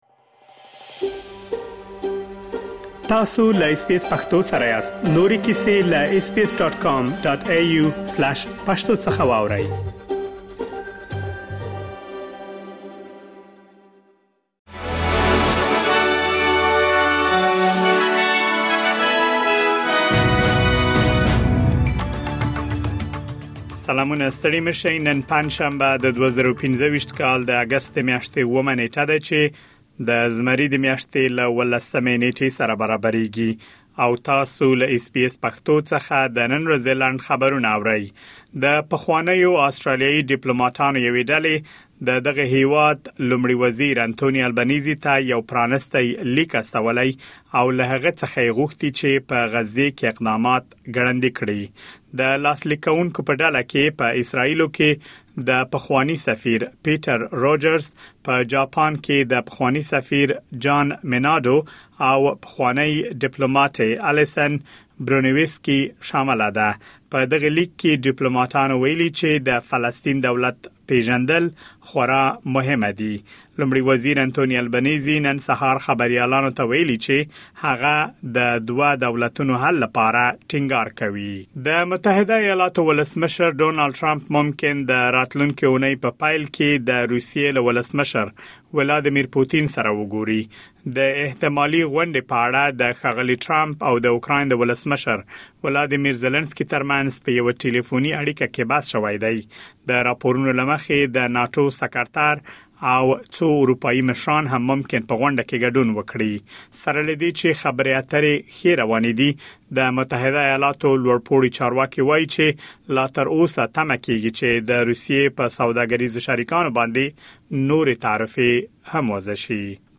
د اس بي اس پښتو د نن ورځې لنډ خبرونه |۷ اګسټ ۲۰۲۵